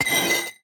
grindstone1.ogg